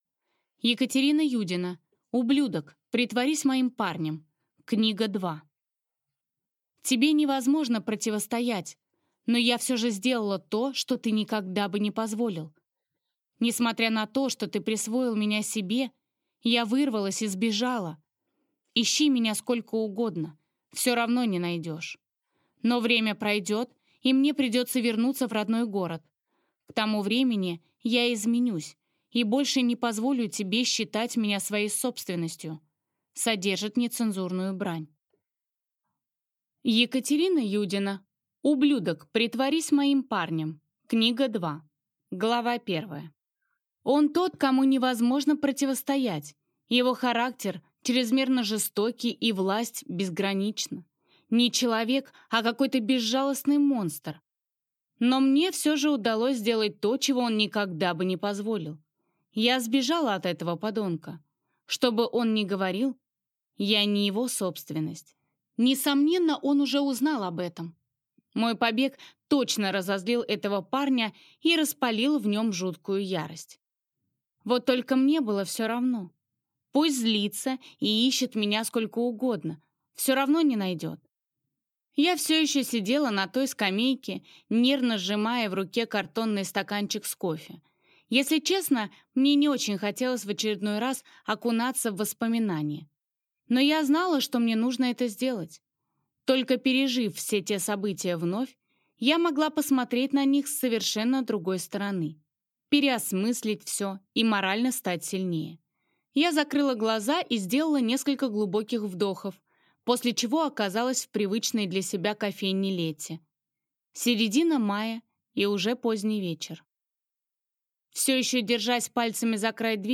Аудиокнига Ублюдок, притворись моим парнем… Книга 2 | Библиотека аудиокниг